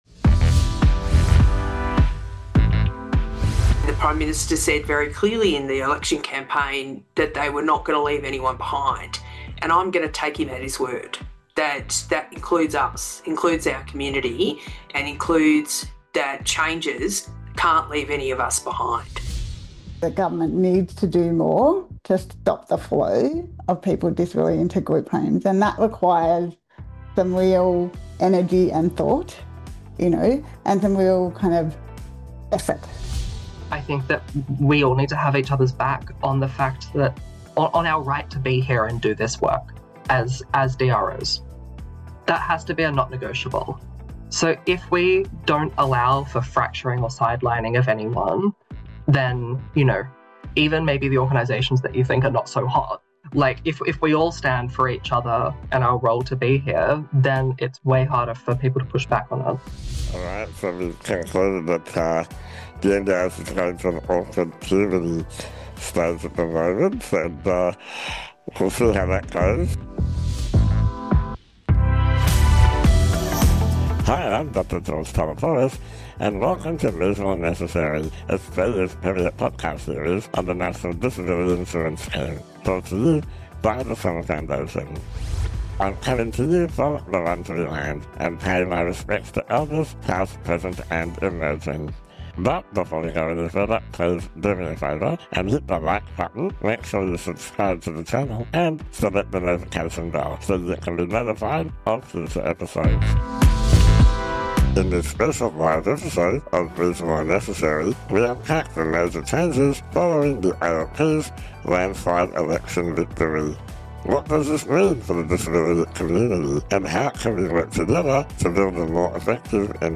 | Reasonable & Necessary LIVE Play episode Jun 10, 2025 52 mins Bookmarks Episode Description What does the new political landscape mean for people with disability and the future of the NDIS? In this special live episode of Reasonable & Necessary